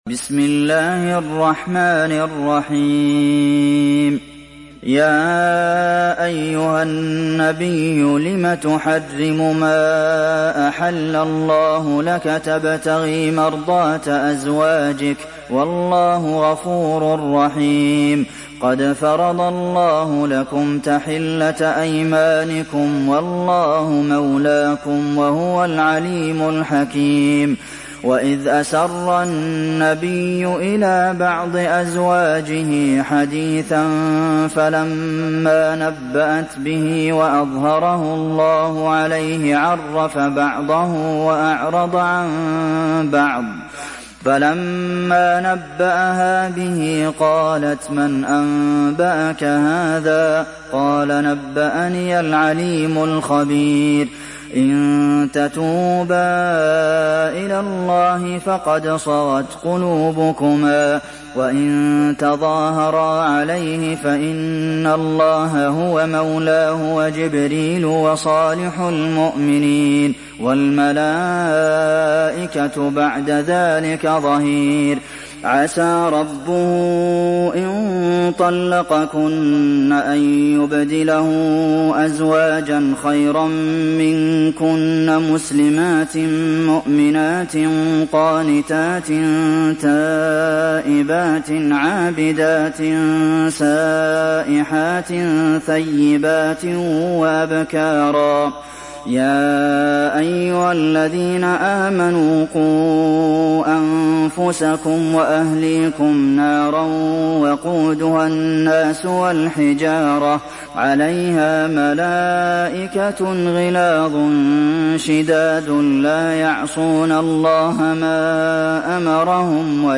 Sourate At Tahrim mp3 Télécharger Abdulmohsen Al Qasim (Riwayat Hafs)